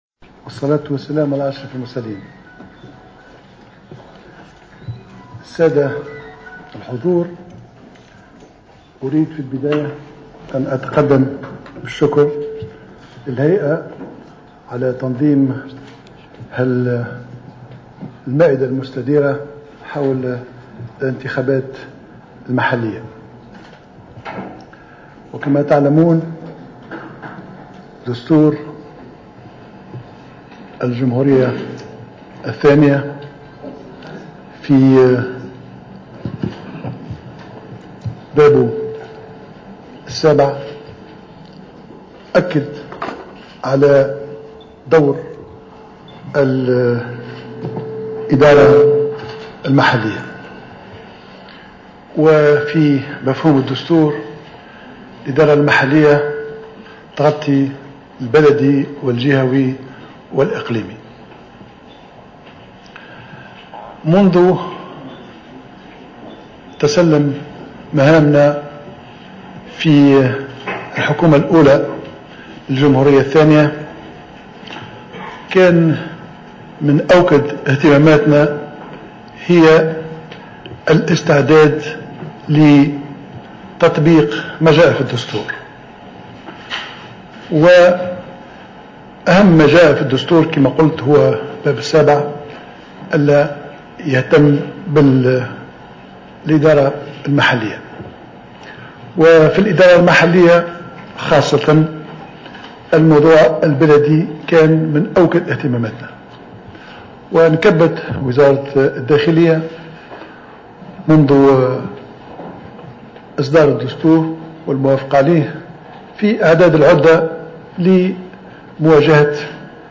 أكد رئيس الحكومة الحبيب الصيد في تصريح اعلامي اليوم الخميس 17 سبتمبر 2015 على هامش مشاركته في مائدة مستديرة حول رزنامة الانتخابات المحلية أن الموضوع البلدي كان ومازال من أوكد اهتمامات الحكومة مشيرا إلى أن وزارة الداخلية انكبت منذ فترة طويلة على إعداد العدة لهذا الموعد الانتخابي وفق قوله.